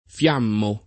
fL#mmo] — voce ant. per «fiammeggiare», di cui sopravvive, con valore di agg., per lo più fig., e (come term. zool.) di s. m., il part. pres. fiammante [